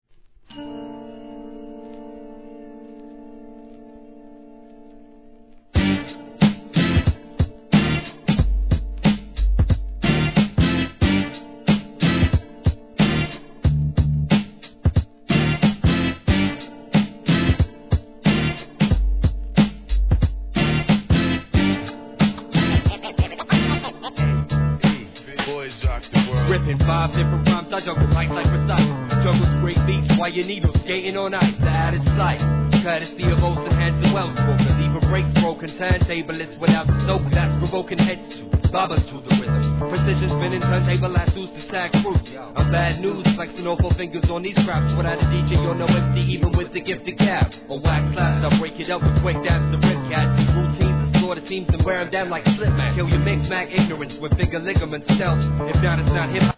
1. HIP HOP/R&B
UKアンダーグラウンド・ヒップホップ！！ネタ感ありのファンキーなアンダーグラウンドもの。